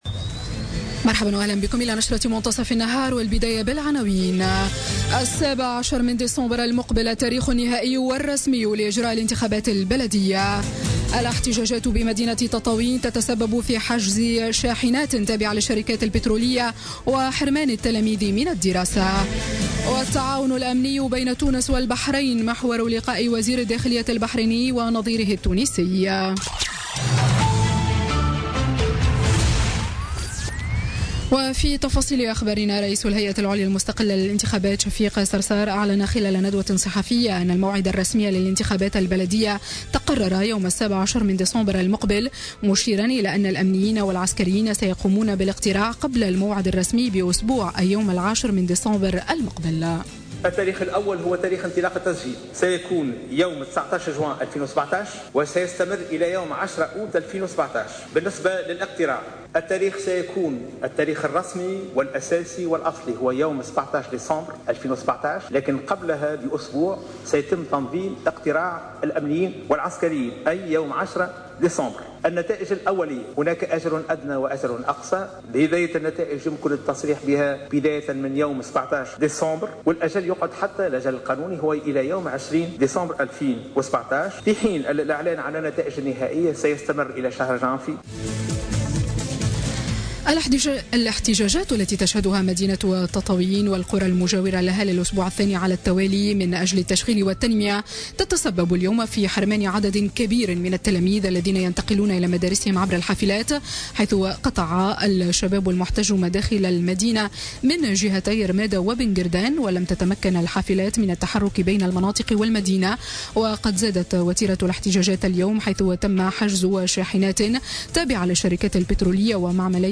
نشرة أخبار منتصف النهار ليوم الإثنين 3 أفريل 2017